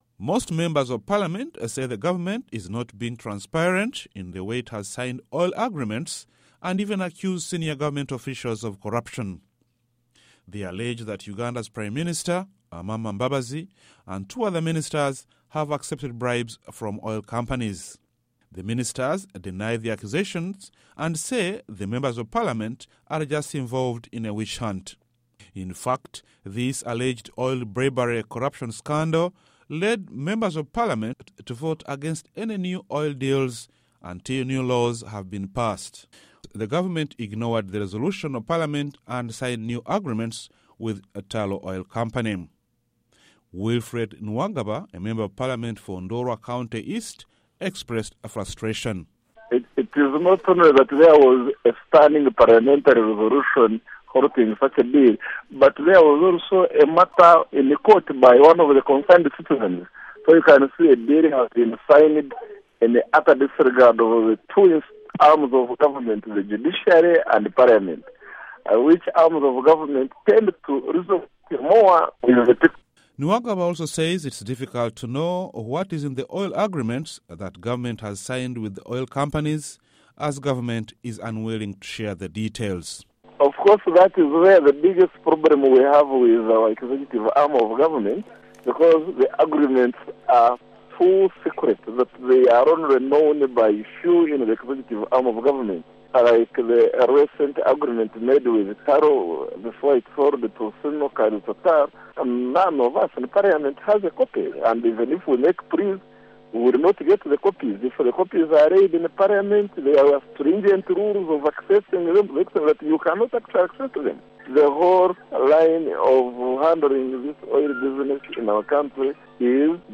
Interview with Wilfred Niwagaba